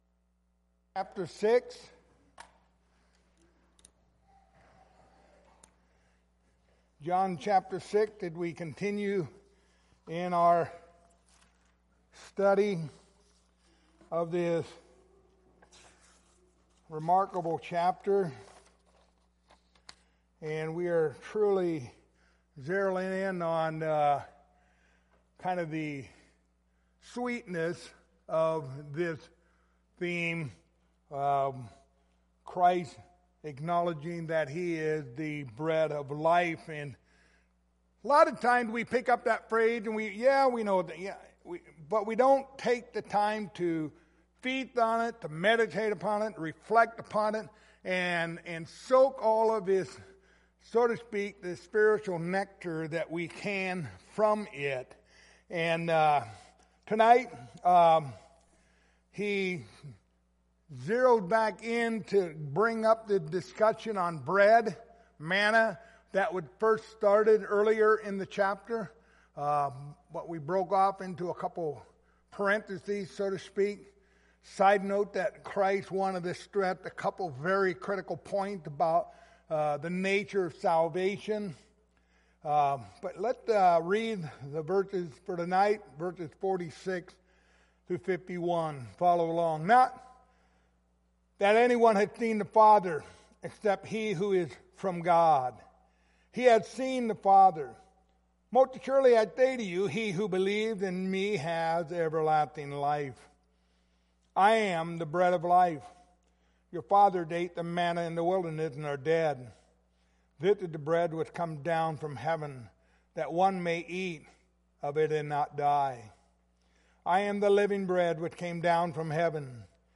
John 6:46-51 Service Type: Wednesday Evening Topics